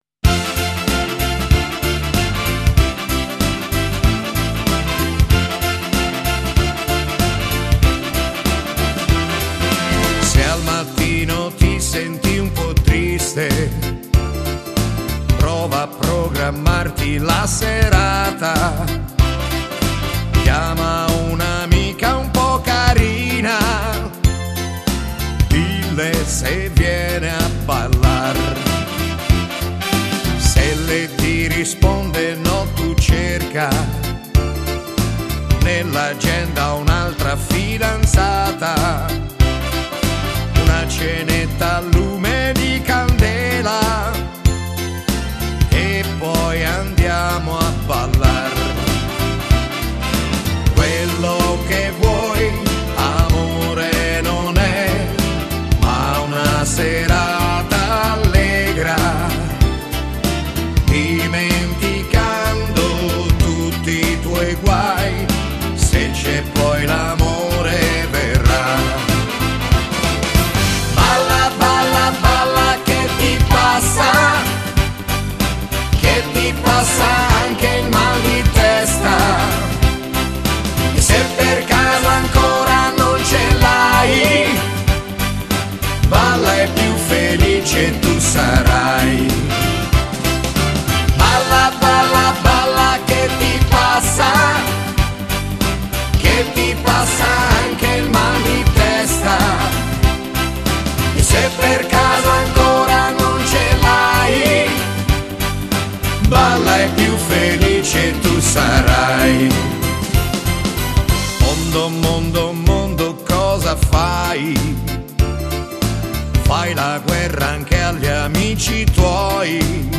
Genere: Moderato fox